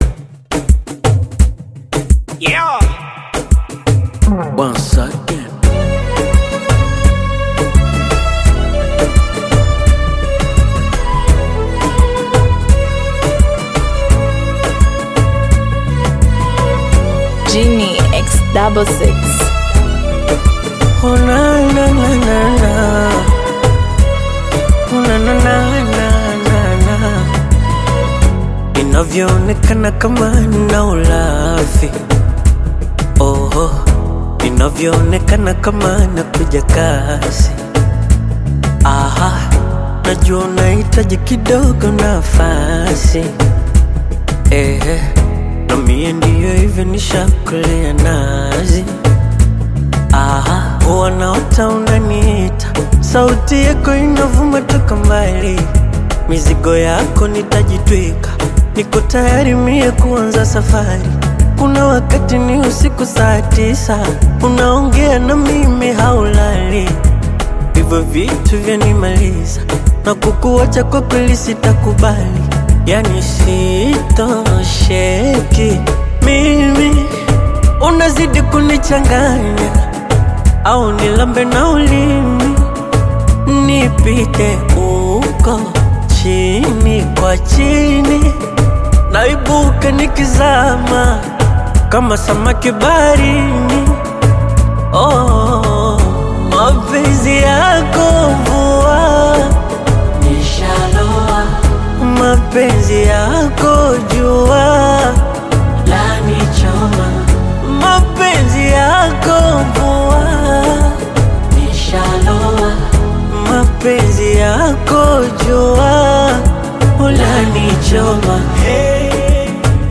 emotional Bongo Flava/Afro-Pop single
Genre: Bongo Flava